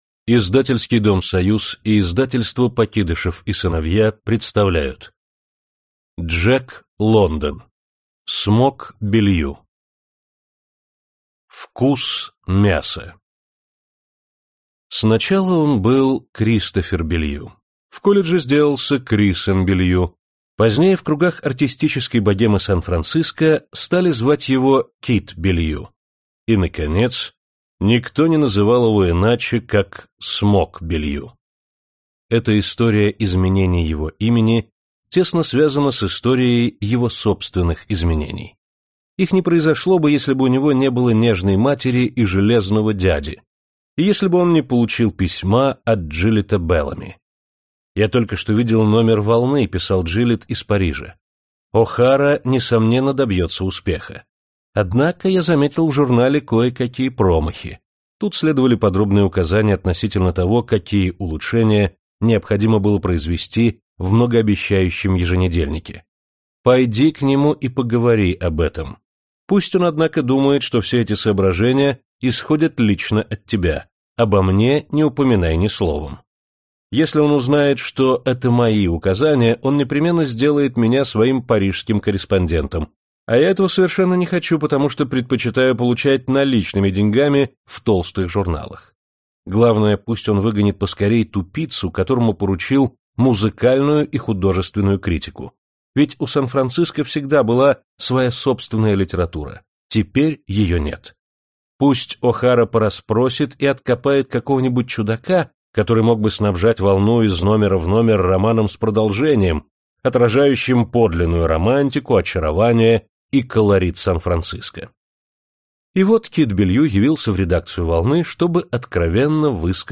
Аудиокнига Смок Беллью | Библиотека аудиокниг